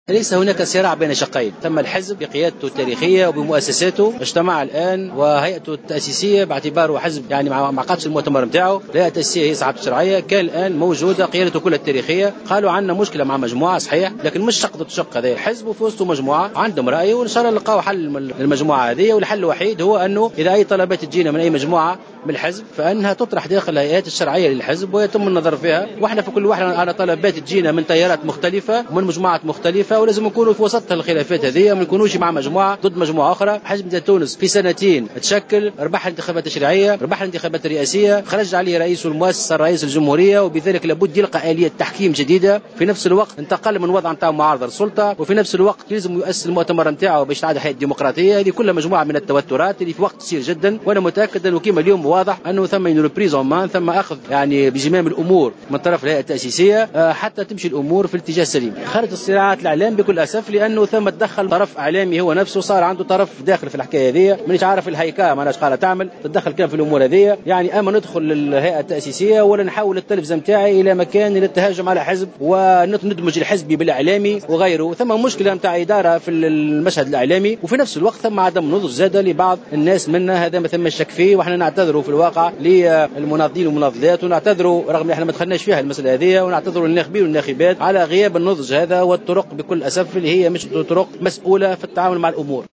نفى القيادي بحزب حركة نداء تونس مُحسن مرزوق خلال اجتماع عقدته الهيْئة التأسيسية اليوم الجمعة 13 مارس 2015 وجود صراع بين شقين داخل الحزب مؤكدا أن الهيأة التأسيسية أعلنت عن وجود خلاف مع مجموعة لها آراء مخالفة في مسائل معينة و سيتم مناقشتها داخل هيئاته الشرعية.